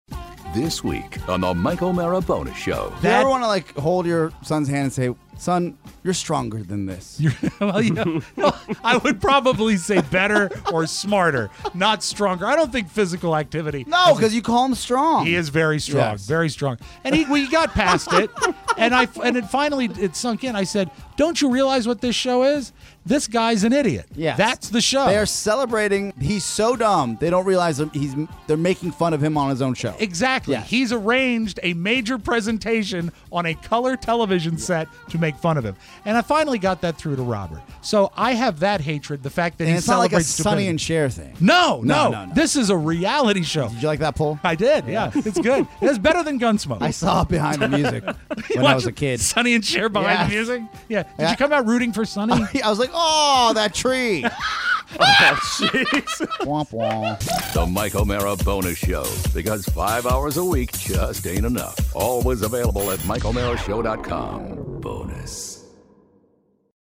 Today we interview the great John McLaughlin! Plus, Lochte Gate – the silver haired douche and favorite movies.